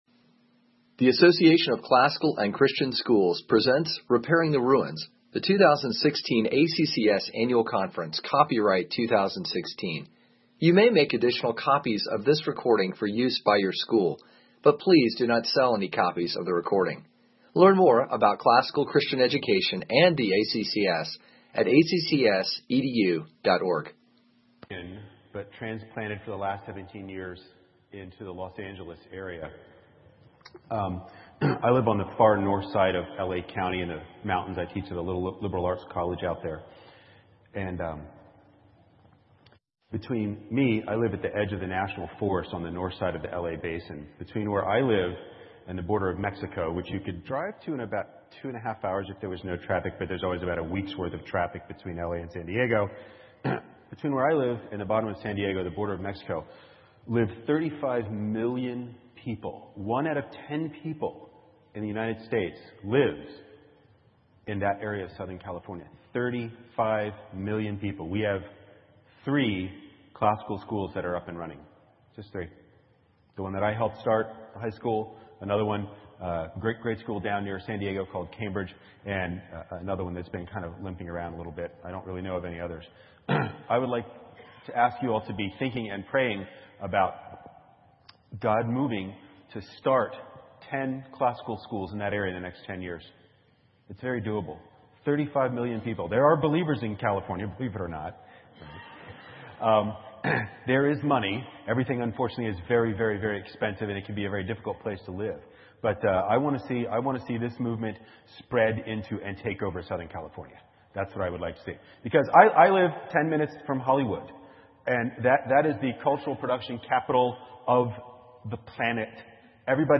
2016 Workshop Talk | 57:57:00 | 7-12, All Grade Levels, Literature, Rhetoric & Composition